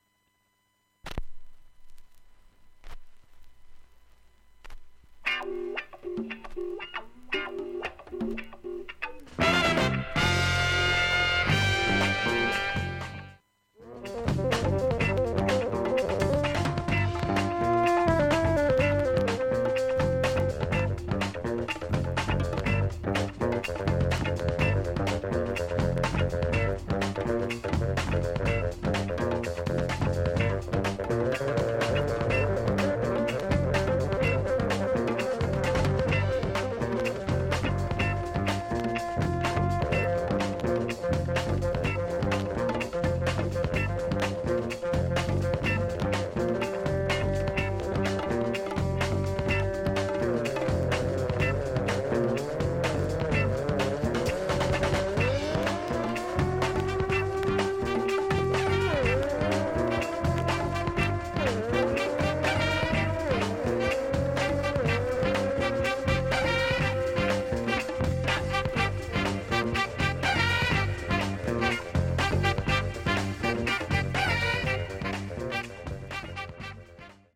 音質良好全曲試聴済み
出だしに2回ほどサッとノイズ2回ほど出ますが
プツ出ますがかすかで気付かないレベルです。
現物の試聴（上記）できます。音質目安にどうぞ